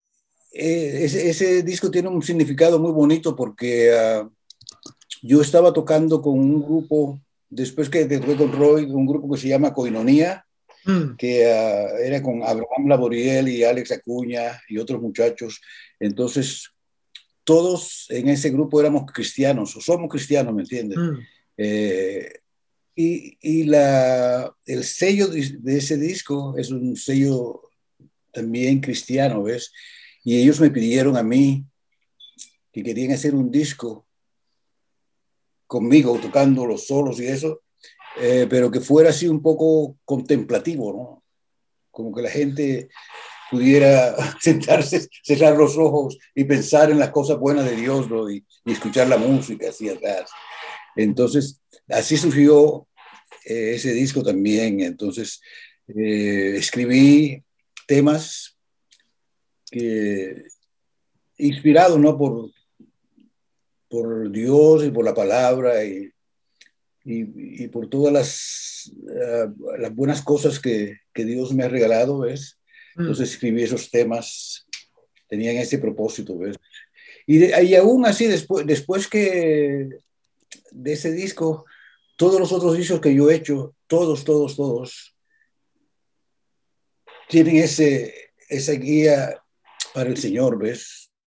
Así como este disco había sido realizado por iniciativa de Roy Ayers y no propia, en 1985 la disquera cristiana con la que grababa Koinonia le encargó el que sería su segundo trabajo discográfico, al que tituló Forever Friends. Escuchemos, en sus propias palabras, el origen y concepto del mismo y, en general, de toda su obra posterior.